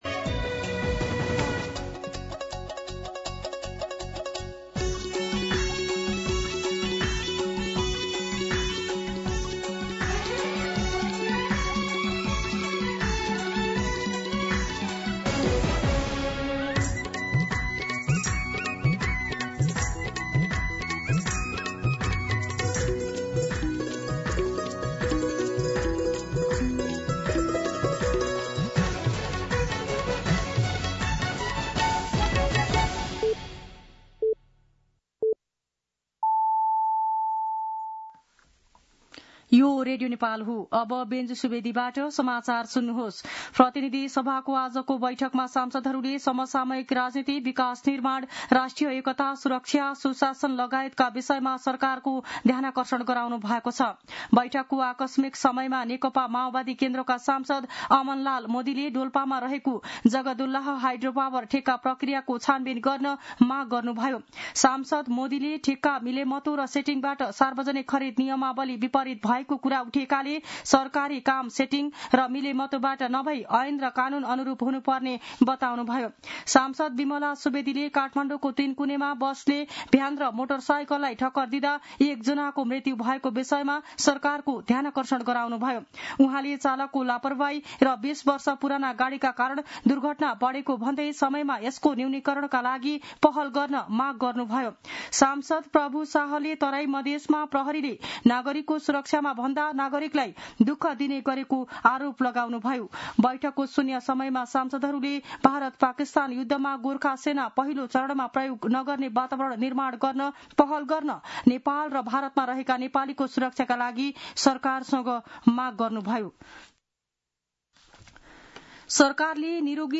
दिउँसो १ बजेको नेपाली समाचार : २५ वैशाख , २०८२
1-pm-Nepali-News-.mp3